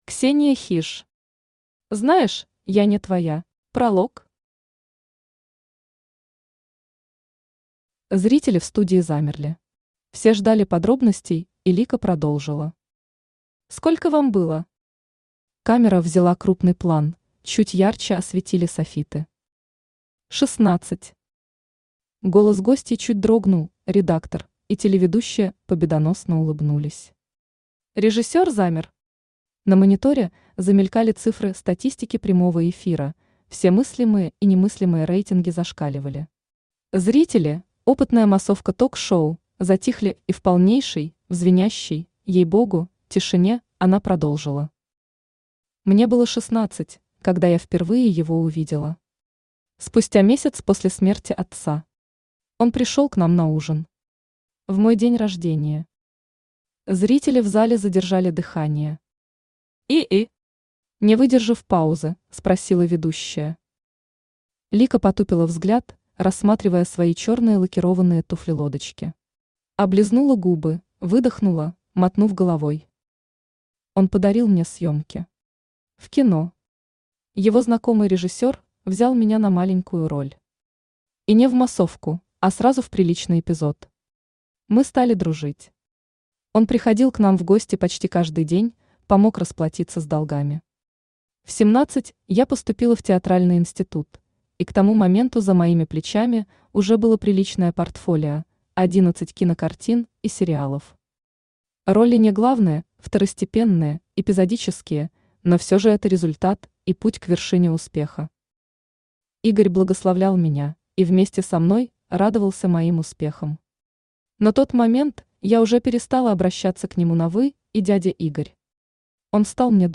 Аудиокнига Знаешь, я не твоя | Библиотека аудиокниг
Aудиокнига Знаешь, я не твоя Автор Ксения Хиж Читает аудиокнигу Авточтец ЛитРес.